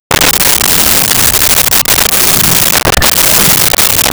Bengal Tiger Roars 01
Bengal Tiger Roars 01.wav